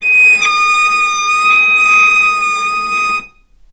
vc_sp-D#6-ff.AIF